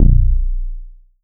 RASCLAT BASS.wav